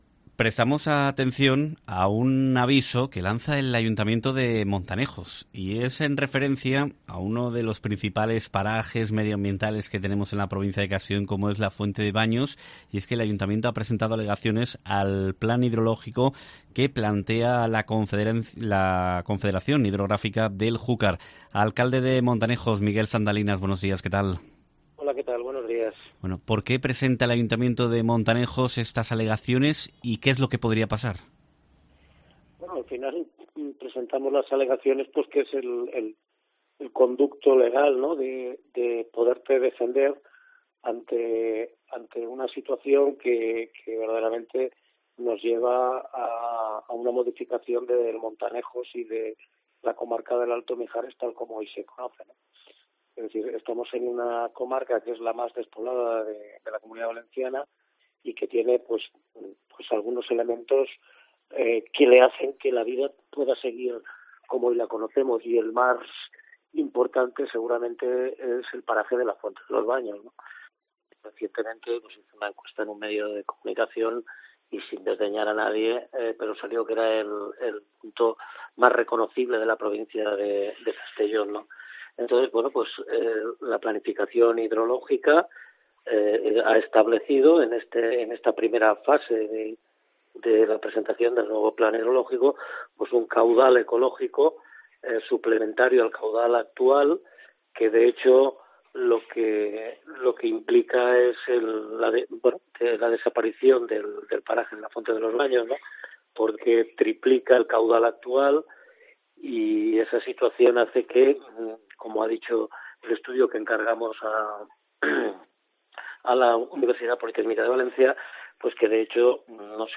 Entrevista
Montanejos ve peligrar Fuente de Baños y alega contra el Plan Hidrológico, como explica en COPE el alcalde, Miguel Sandalinas